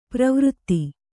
♪ pravřtti